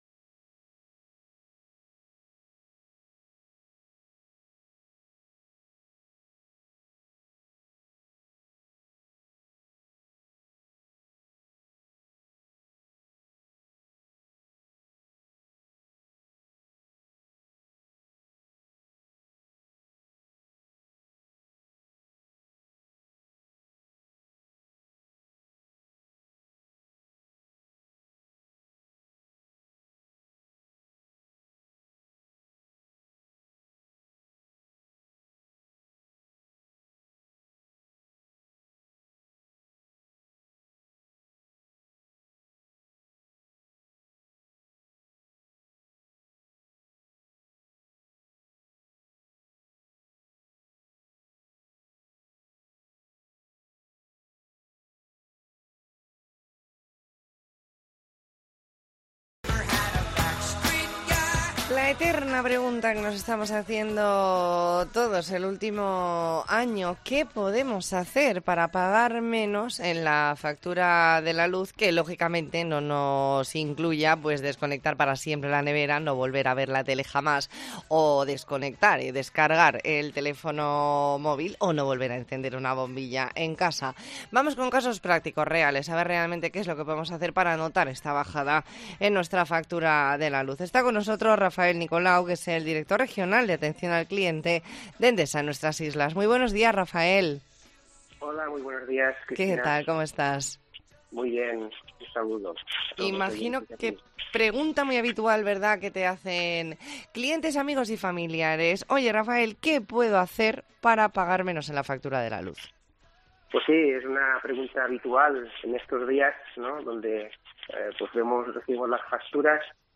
E ntrevista en La Mañana en COPE Más Mallorca, lunes 19 de septiembre de 2022.